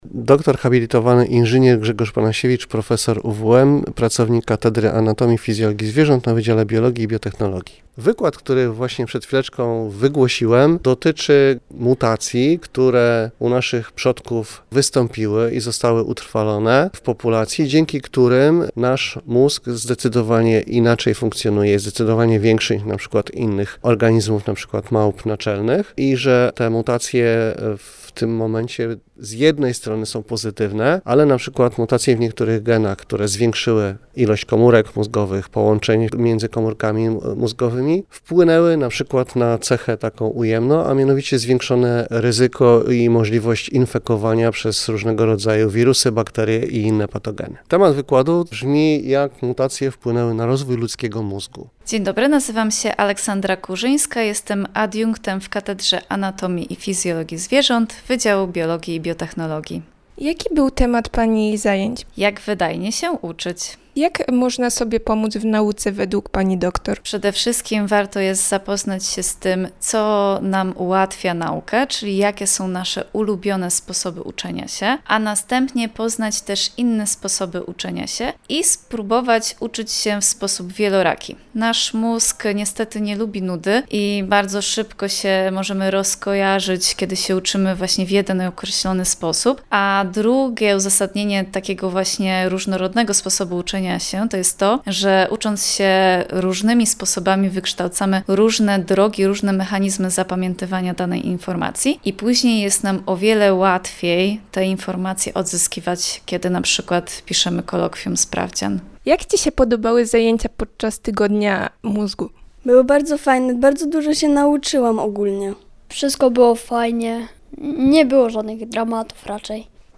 – mówili nam uczniowie, którzy odwiedzili w piątek Wydział Biologii i Biotechnologii UWM.